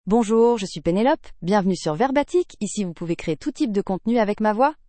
PenelopeFemale French AI voice
Penelope is a female AI voice for French (France).
Voice sample
Listen to Penelope's female French voice.
Female
Penelope delivers clear pronunciation with authentic France French intonation, making your content sound professionally produced.